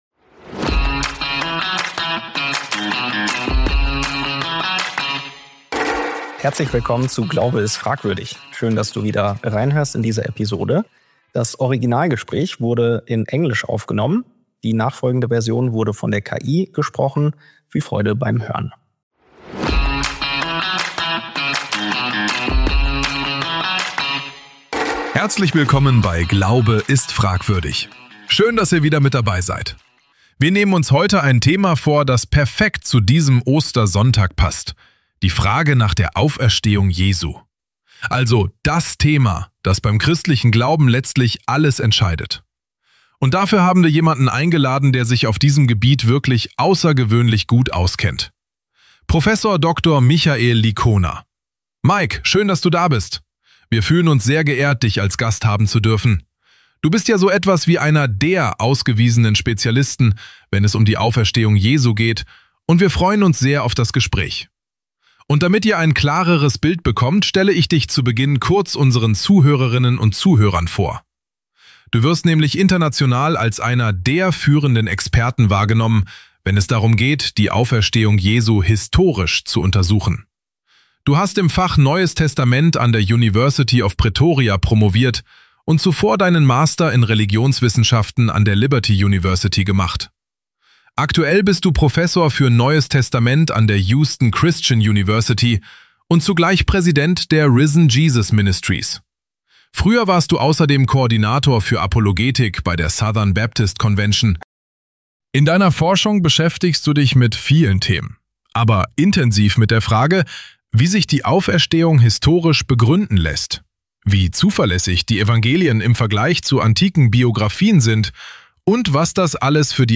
Fakt oder Fiktion? Die Auferstehung Jesu - Ein Gespräch